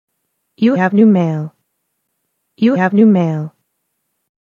computer - e-mail